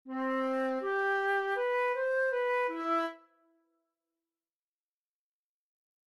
Ligadura de expresión